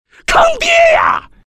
男人大喊坑爹呀音效_人物音效音效配乐_免费素材下载_提案神器